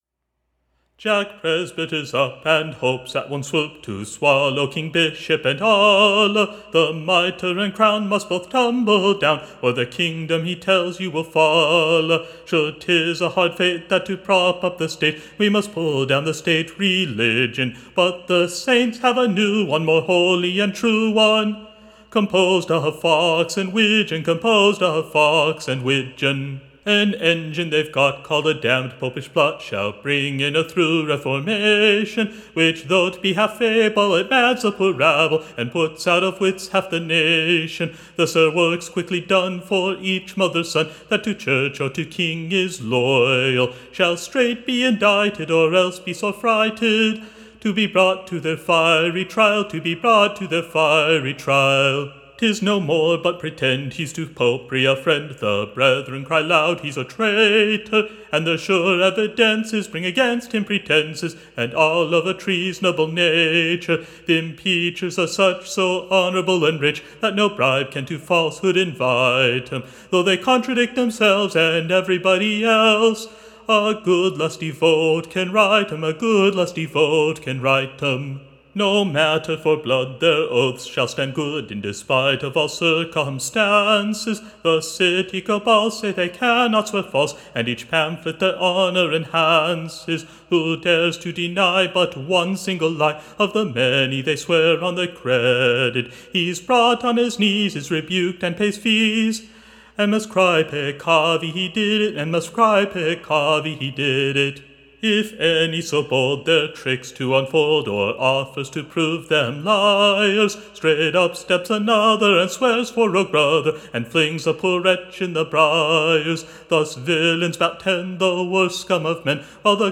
Recording Information Ballad Title THE / PRESENT STAT[E] / OF / ENGLAND[:] / A Pleasant New True Ballad, Tune Imprint To the Tune of, The Taylor and his Lass: Or, It was in the Pr[ime,] / (Of Coucumber [Time.]